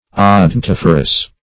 Odontophorous \O`don*toph"o*rous\, a.
odontophorous.mp3